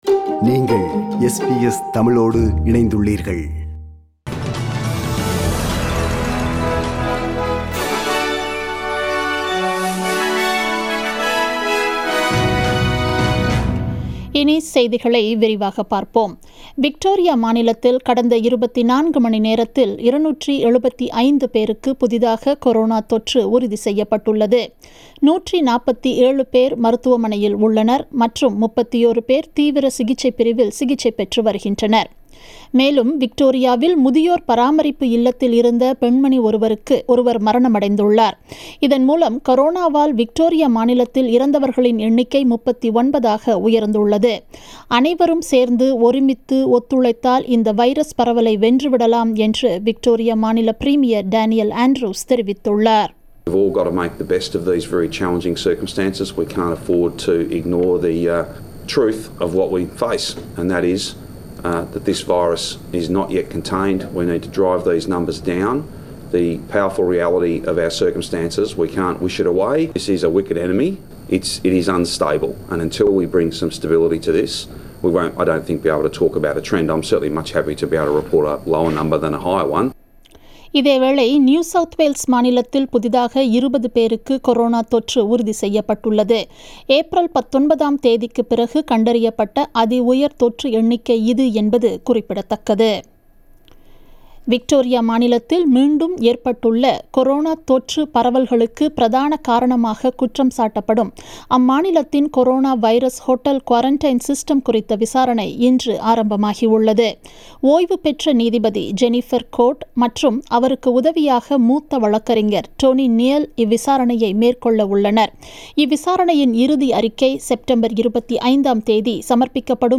The news bulletin aired on 20 July 2020 at 8pm